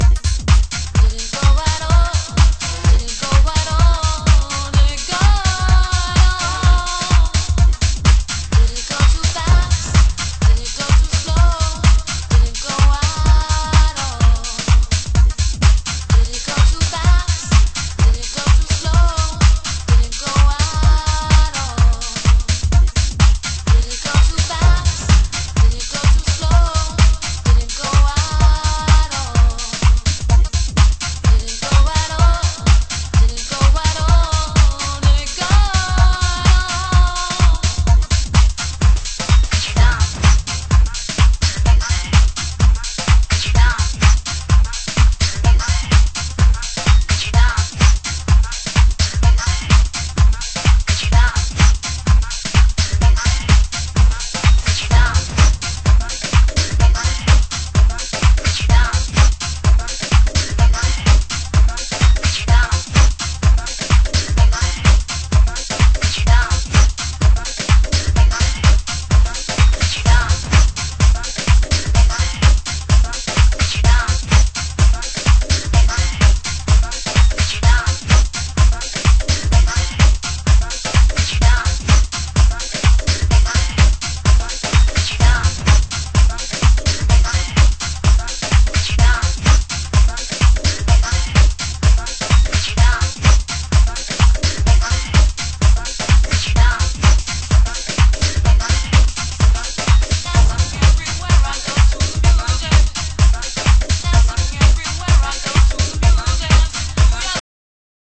盤質：少しチリパチノイズ有/B1に盤面汚れによるプチノイズ有